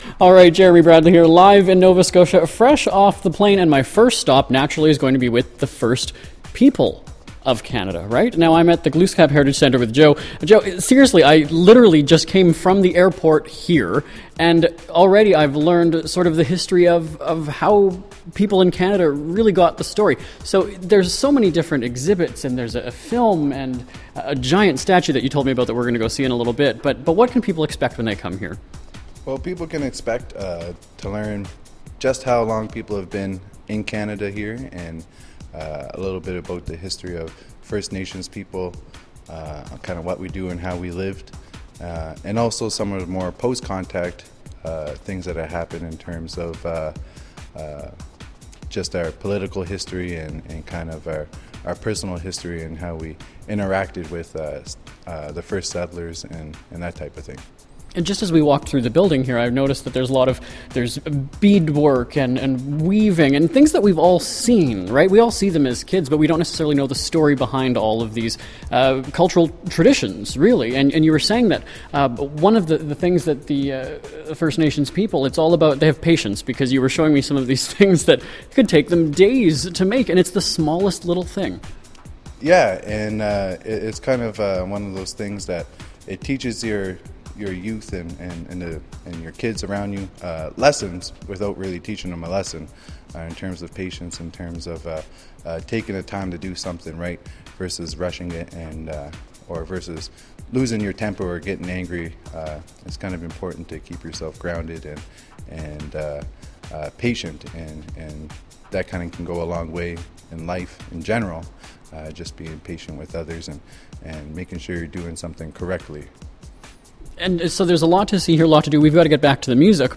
Live Event